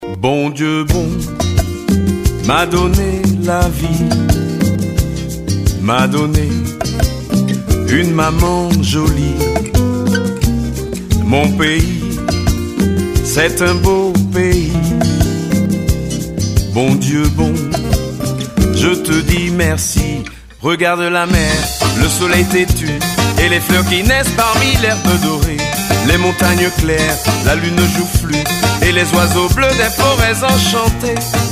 Guitare Solo